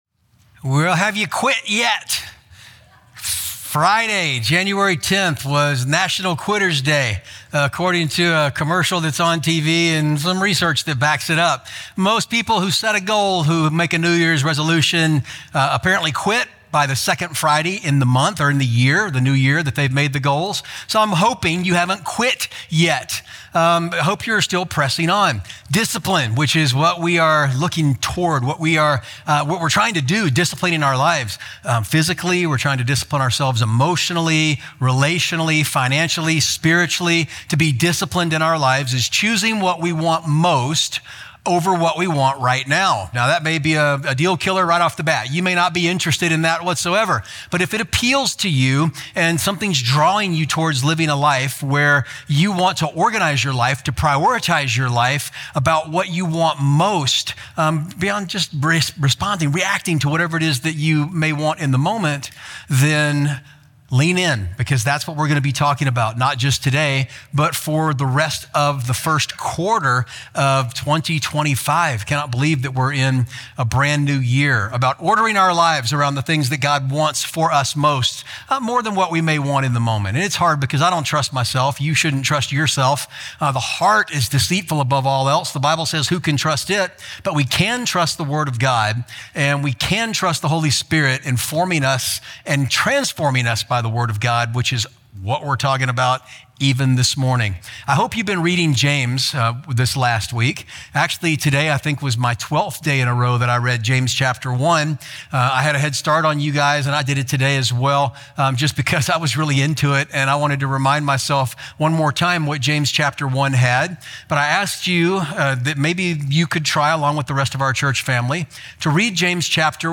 Capitol City Church Podcast (Sermon Audio) (Sermon Audio)